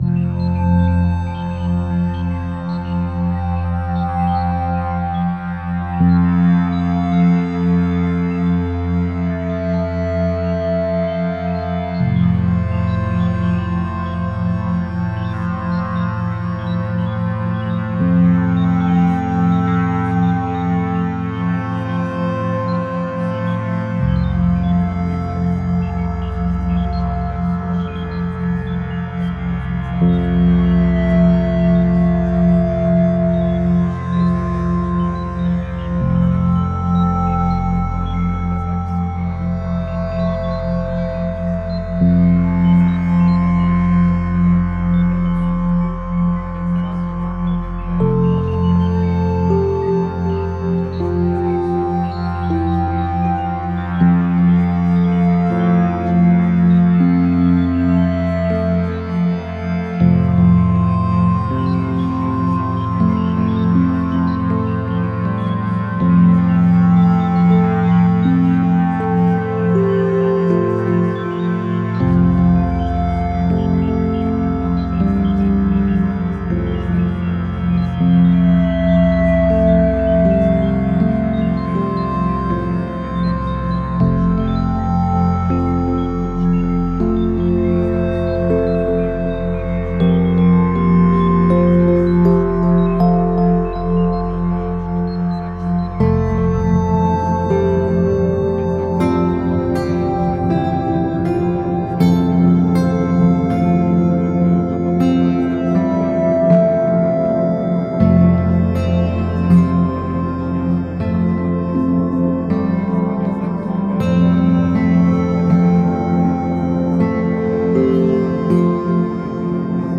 (Version RELAXANTE)
Alliage ingénieux de sons et fréquences curatives, très bénéfiques pour le cerveau.
SAMPLE-Coherence-magnetique-relaxant.mp3